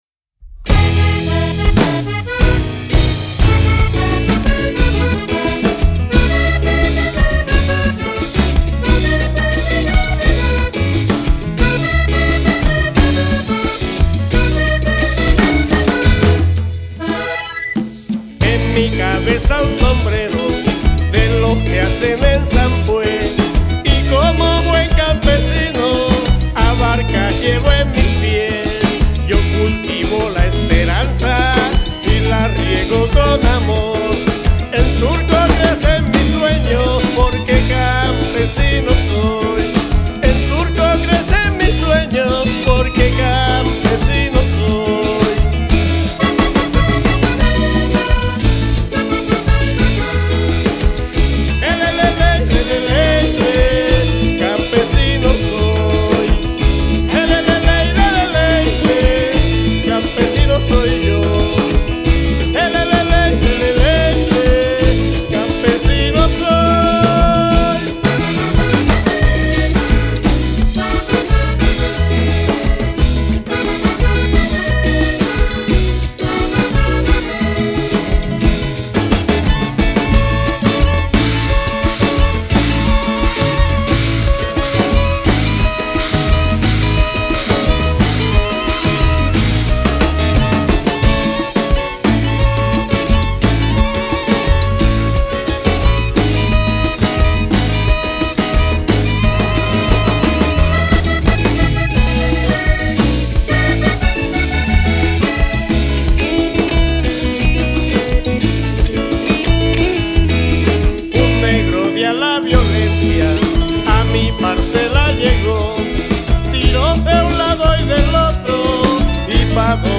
Canción
voz.
tambora.
tambor alegre.
acordeón.
gaita hembra.
gaita macho.
batería.
piano.
clarinete.
guitarra y arreglos.
bajo y arreglos.